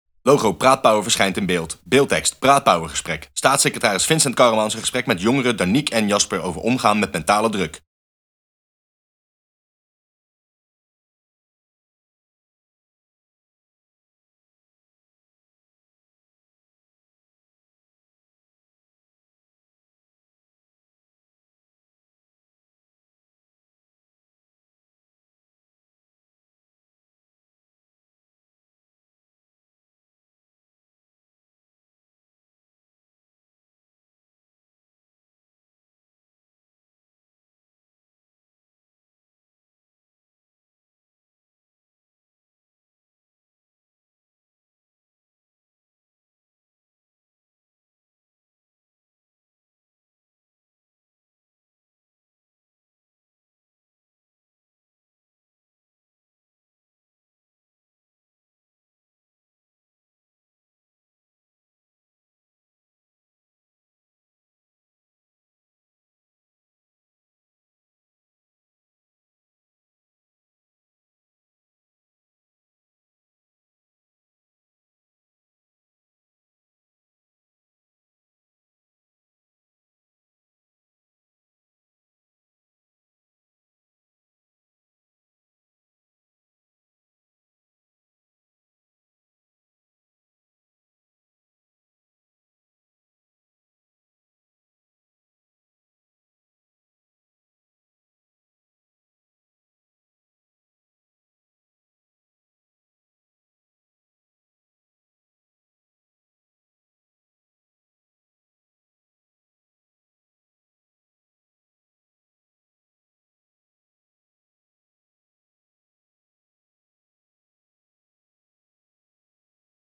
Video: Staatssecretaris in gesprek met jongeren over mentale druk | voor Jeugd & Gezin